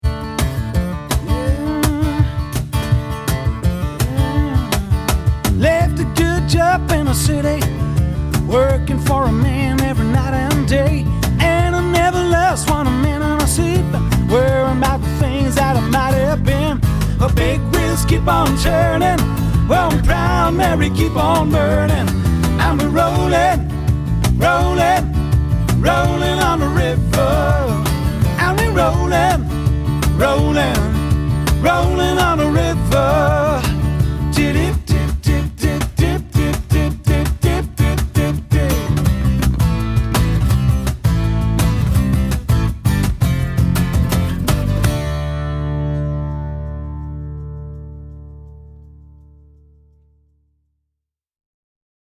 Trio
Rock & Funk mit Stil und noch mehr Energie.